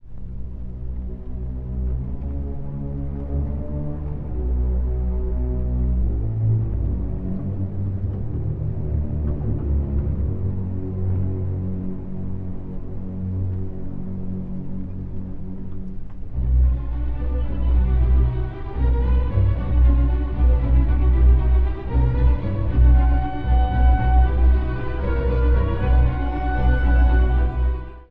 第1楽章 内なる葛藤と高揚感
（序奏～第一主題） 古い音源なので聴きづらいかもしれません！
冒頭、低弦がそっとつぶやくように始まる主題が、この曲全体のキーワード。